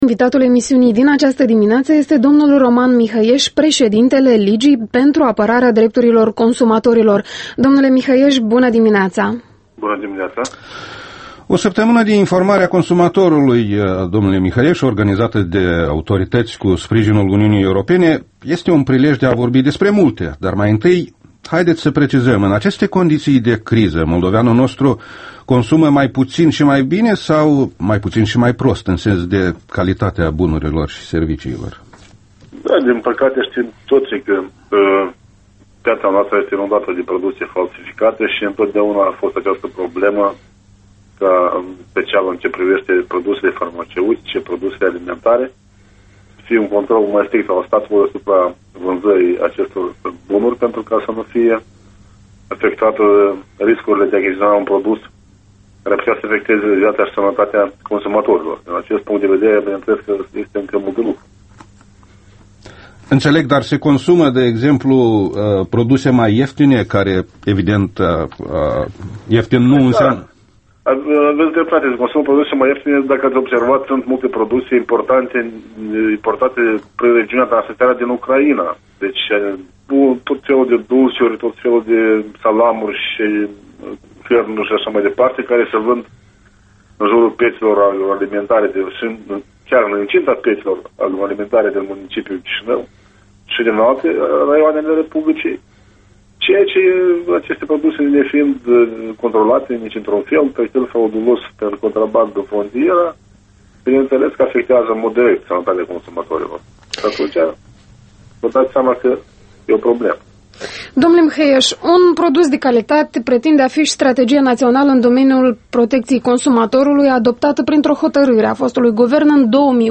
Interviu matinal EL